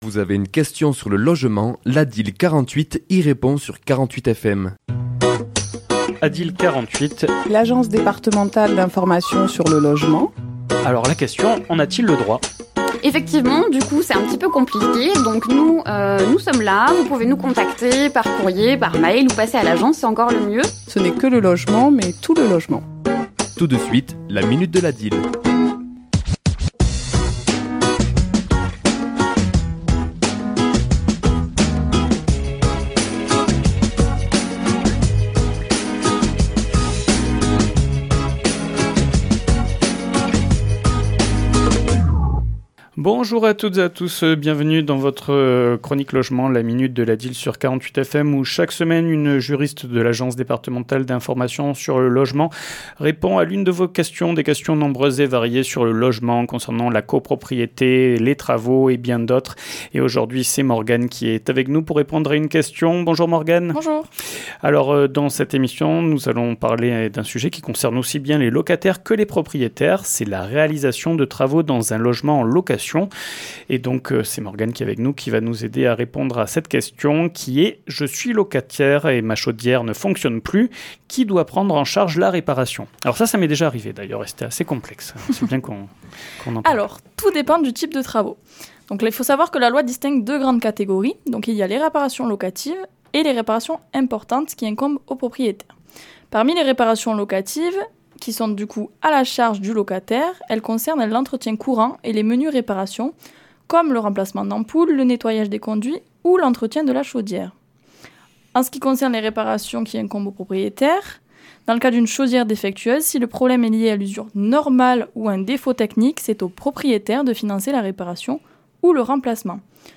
Chronique diffusée le mardi 6 mai à 11h et 17h10